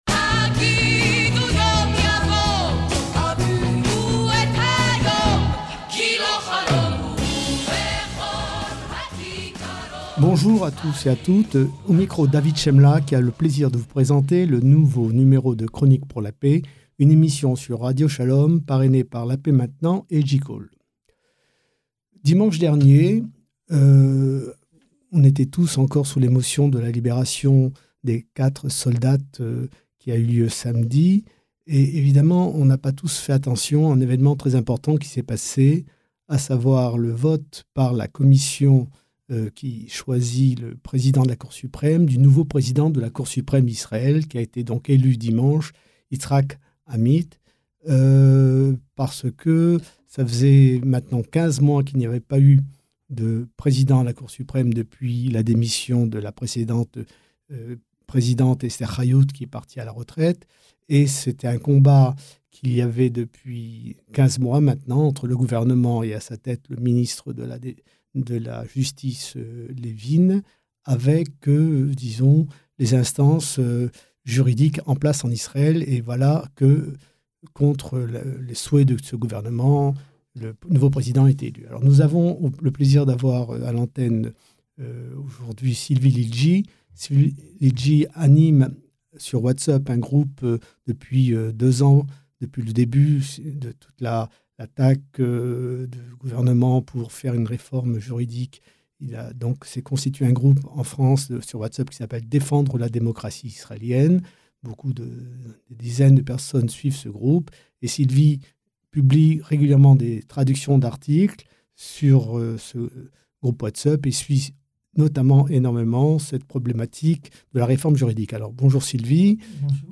émission bimensuelle sur Radio Shalom parrainée par La Paix Maintenant et JCall